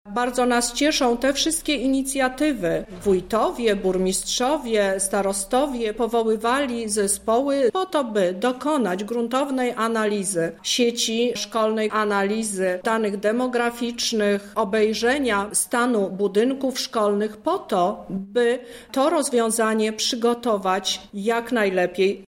reforma – mówi Lubelska Kurator Oświaty, Teresa Misiuk